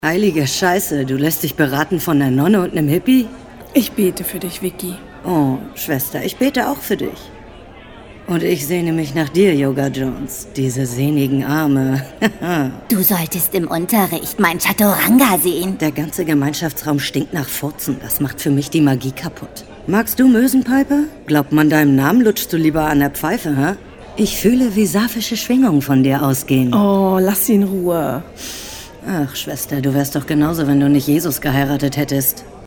dunkel, sonor, souverän
Mittel plus (35-65)
Lip-Sync (Synchron)